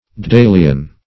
Daedal \D[ae]"dal\, Daedalian \D[ae]*dal"ian\, a. [L. daedalus